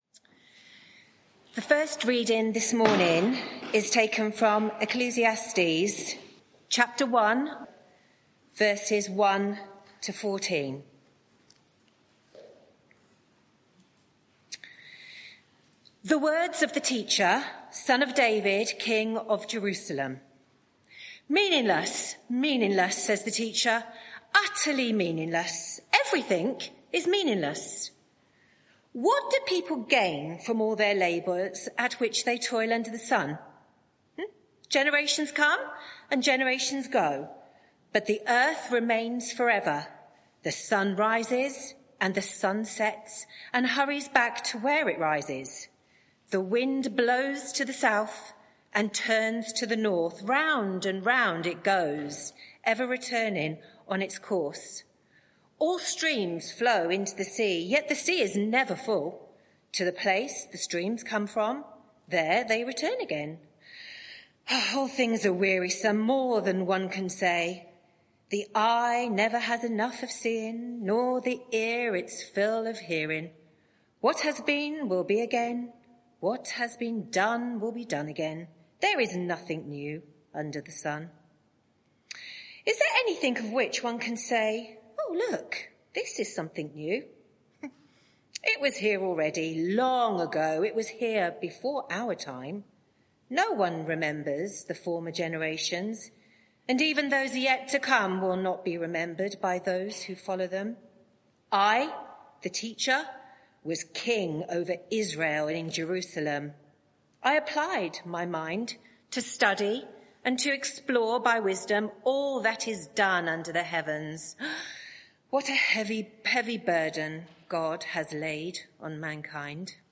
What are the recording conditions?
Media for 11am Service on Sun 15th Sep 2024 11:00 Speaker